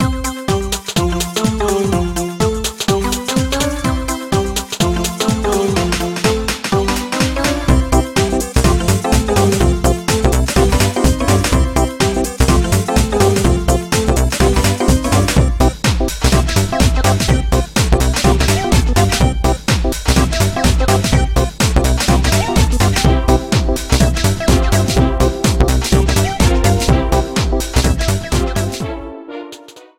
The music for menus